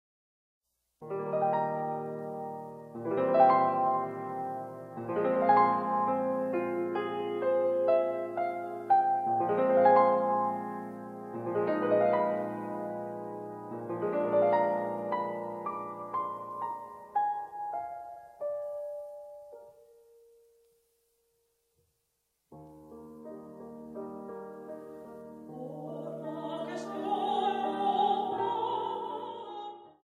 piano.
Grabado: Estudio B, IMER, 1996, Piano Steinway